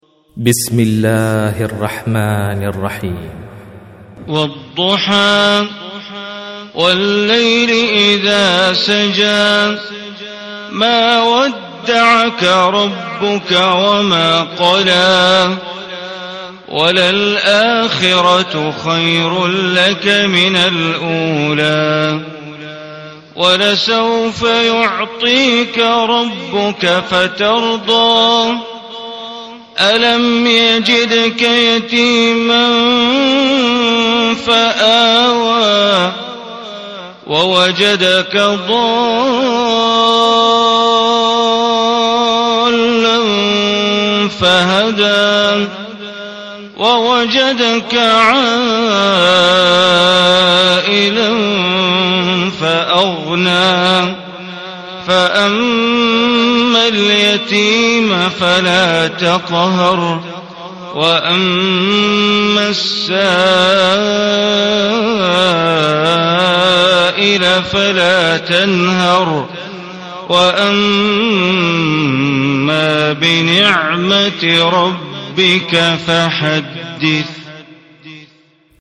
Surah ad-Duha Recitation by Sheikh Bandar Baleela
Surah ad-Duha, is 93 chapter of Holy Quran. Listen or play online mp3 tilawat / recitation in Arabic in the beautiful voice of Sheikh Bandar Baleela.